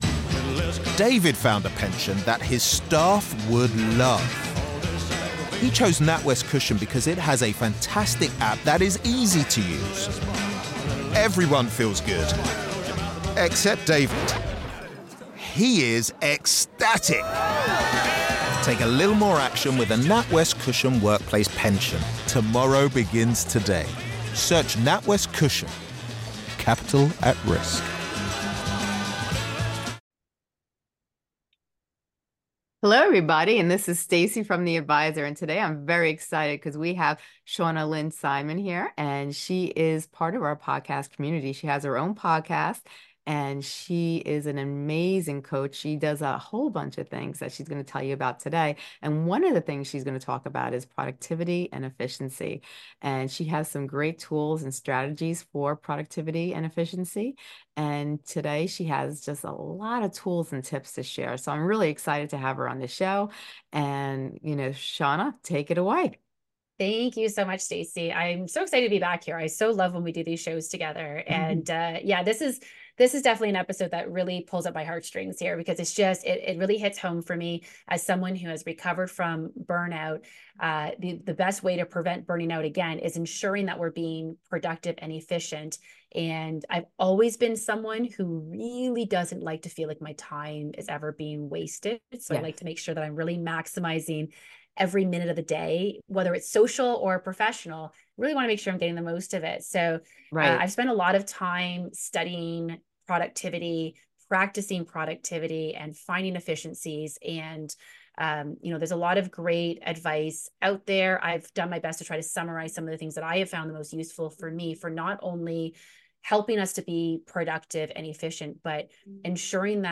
The podcast featured a conversation between two speakers discussing the productivity paradox, where being busy does not necessarily mean being productive. They emphasized the importance of focusing on high-level work that moves business or personal goals forward, avoiding distractions, delegating tasks effectively, and prioritizing tasks to achieve maximum productivity.